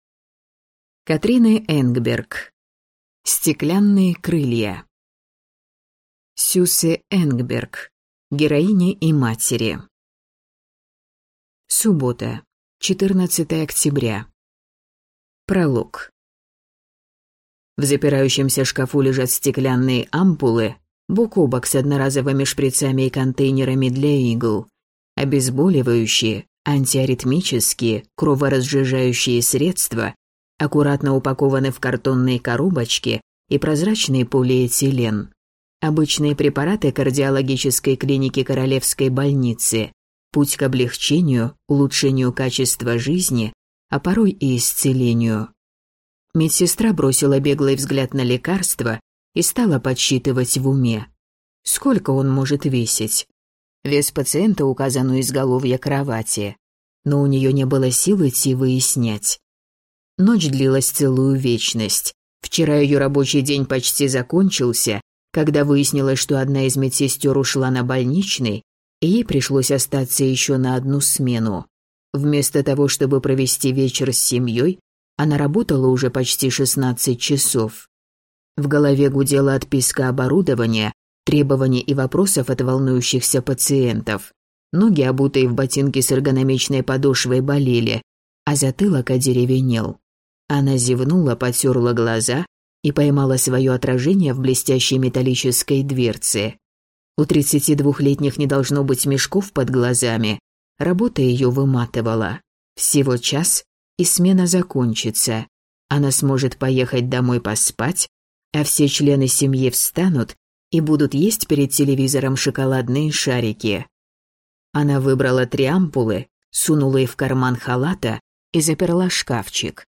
Аудиокнига Стеклянные крылья | Библиотека аудиокниг